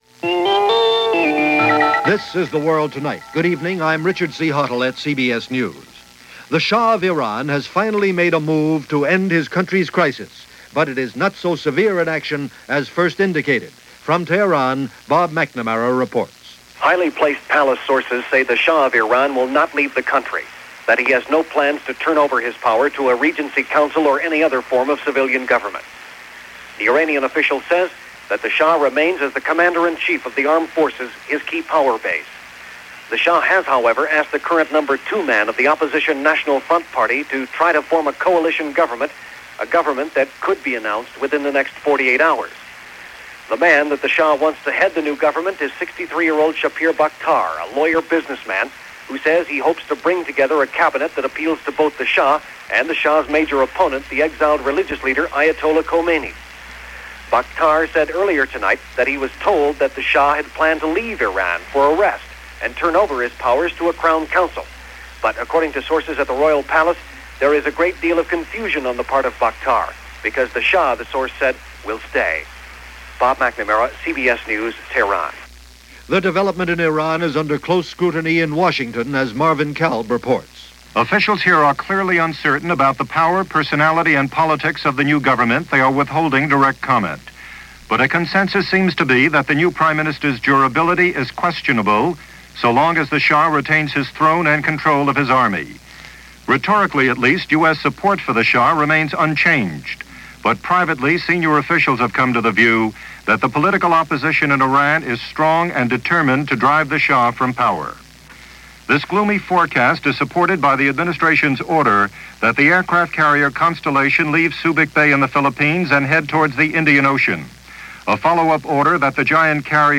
And that’s just a little of what went on, this December 29, 1978 as broadcast by The World Tonight from CBS Radio.